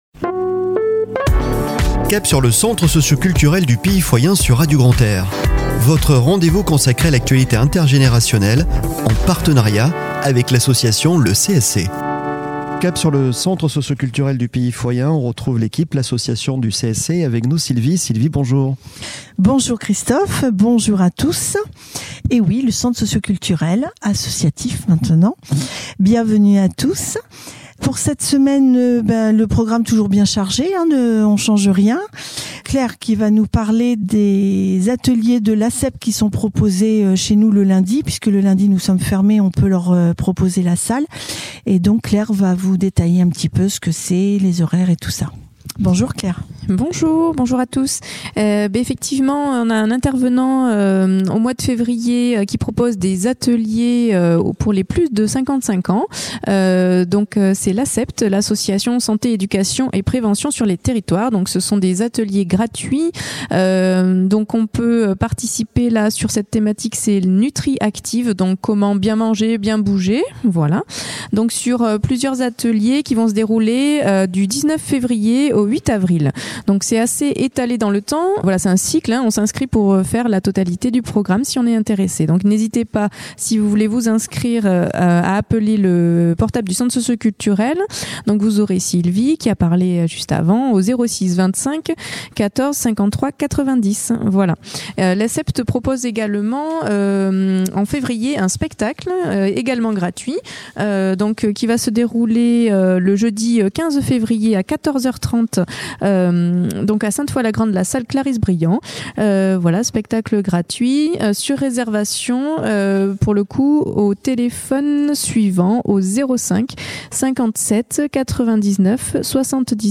Chronique de la semaine du 29 Janvier au 04 Février 2024 !